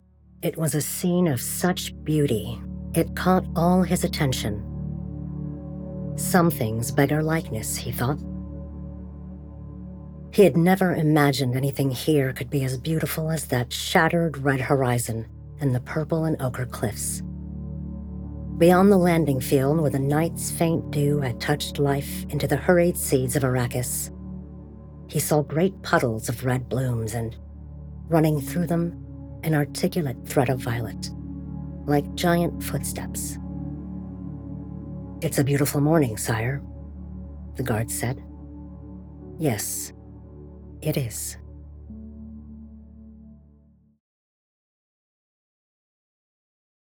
Audiobook Narration
I have a naturally rich, deep voice that exudes confidence while maintaining authenticity and relatability.